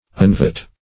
Search Result for " unvote" : The Collaborative International Dictionary of English v.0.48: Unvote \Un*vote"\, v. t. [1st pref. un- + vote.] To reverse or annul by vote, as a former vote.